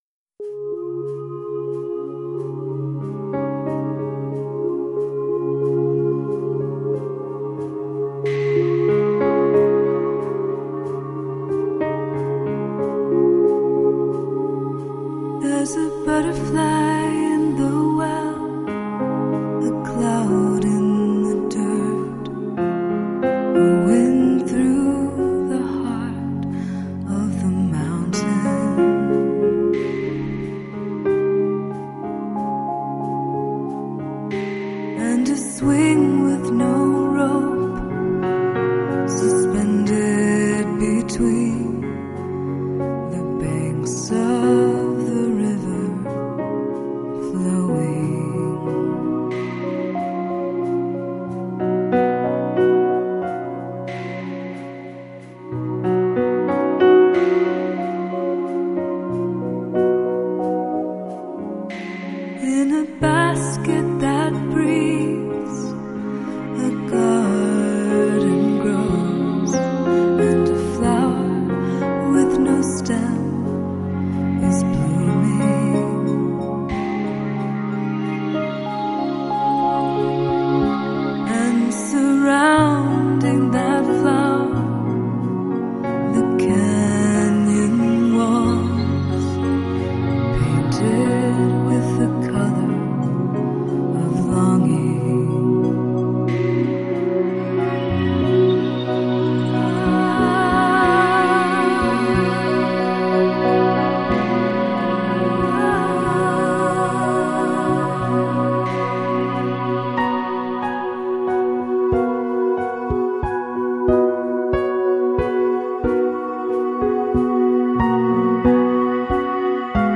结合中东、西班牙、非洲等地的音乐弦律首次创作2首新世 纪音乐氛围的演唱曲。